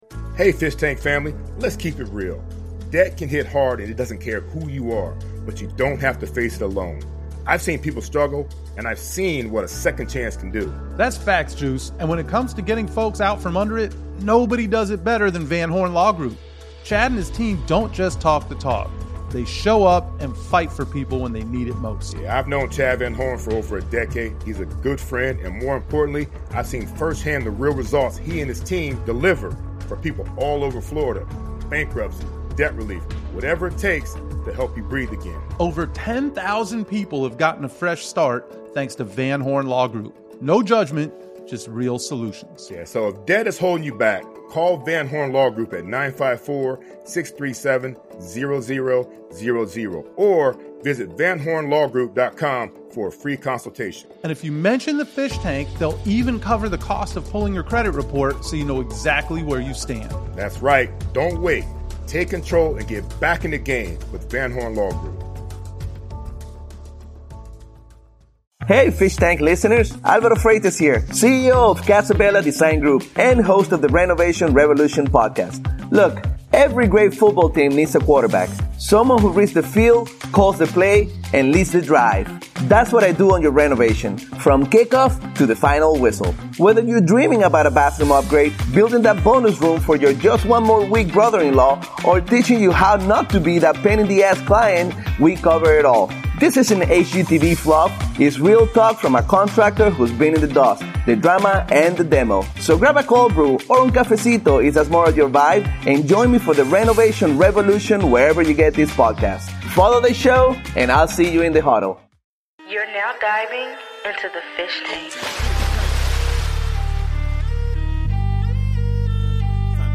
Smart football talk, nonstop laughs, and unfiltered Dolphins conversation